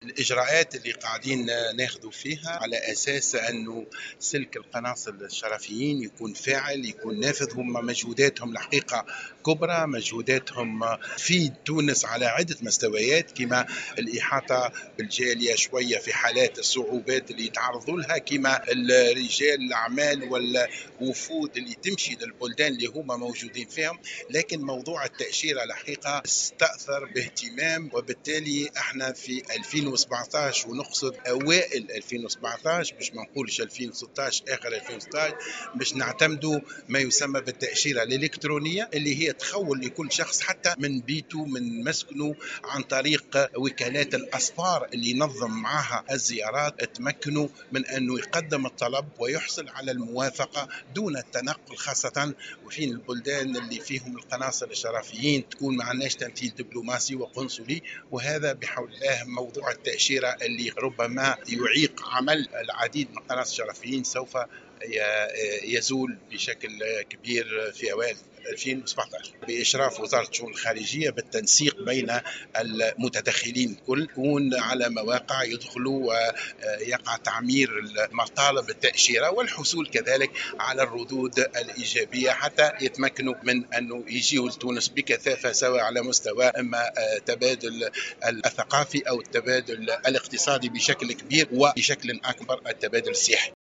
وجاءت تصريحاته على هامش الندوة العامة الأولى للقناصل الشرفيين اليوم باشراف وزير الخارجية خميس الجهيناوي.